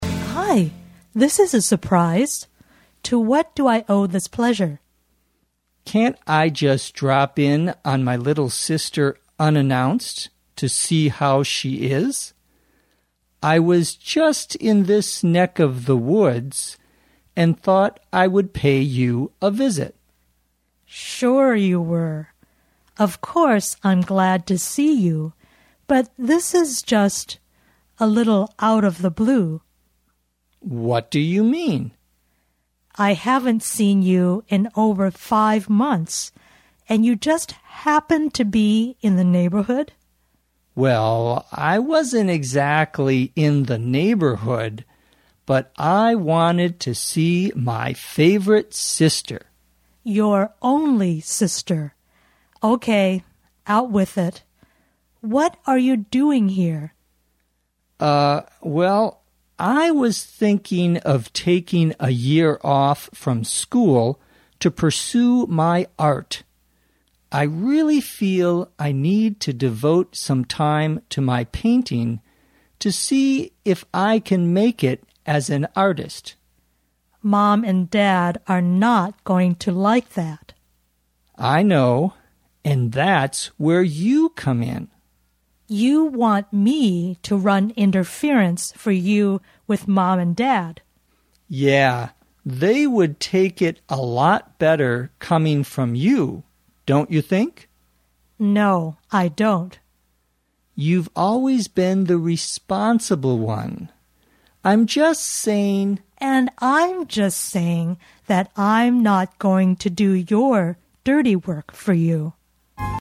地道美语听力练习:不速之客